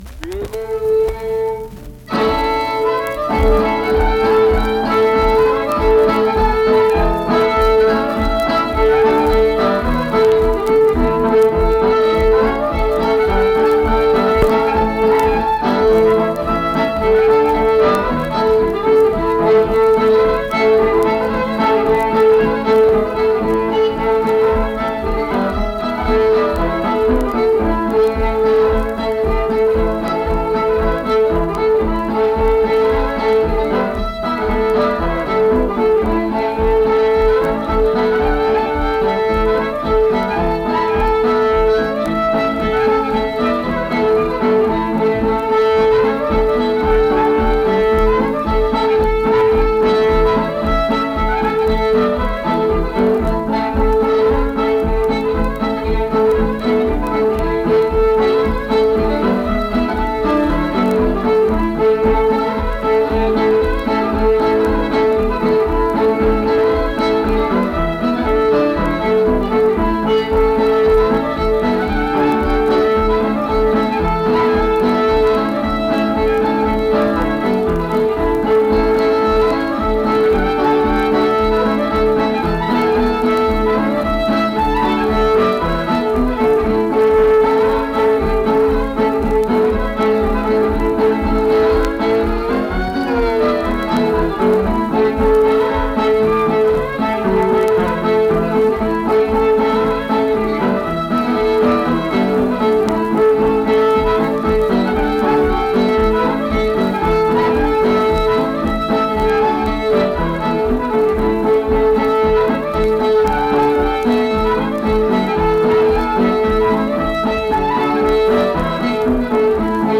Accompanied guitar and unaccompanied fiddle music performance
Instrumental Music
Fiddle, Guitar
Pocahontas County (W. Va.), Mill Point (W. Va.)